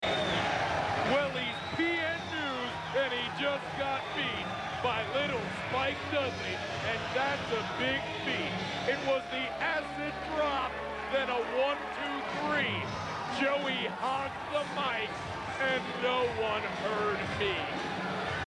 to bust out a rap of his own!